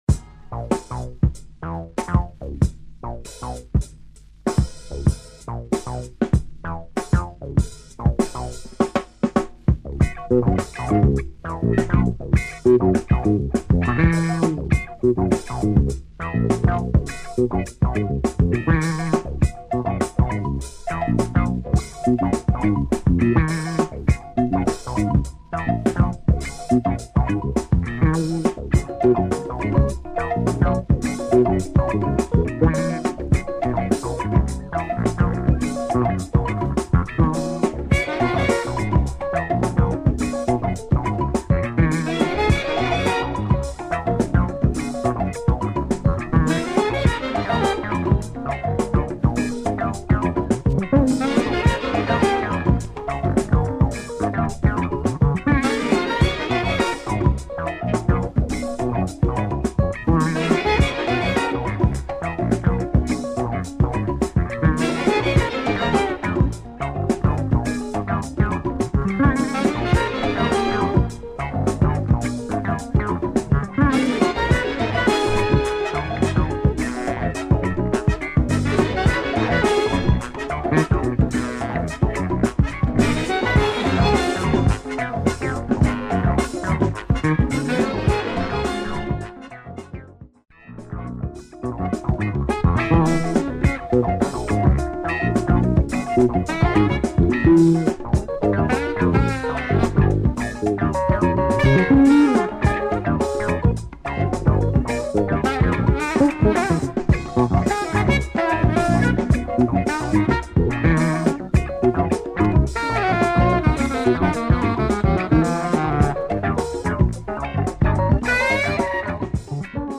are two nice big band funk tunes
Dope Polish breaks !